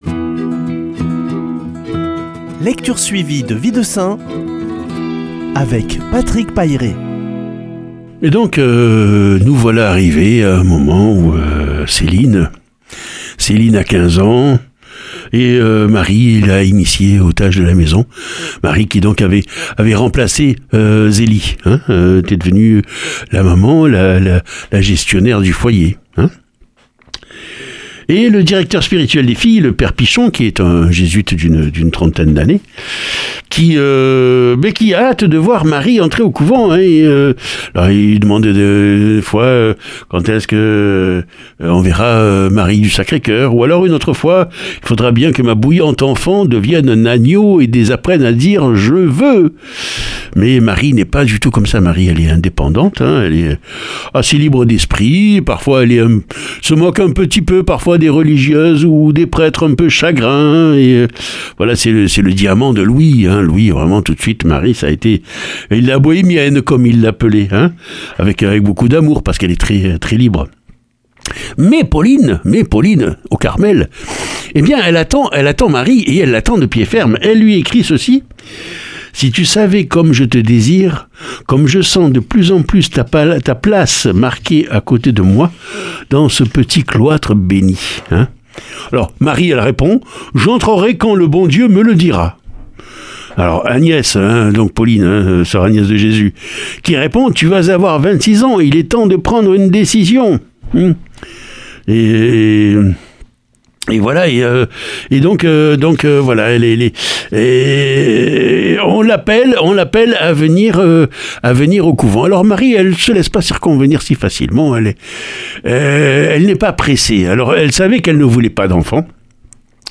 lundi 14 juin 2021 Récit de vie de saints Durée 2 min
Lecture suivie de la vie des saints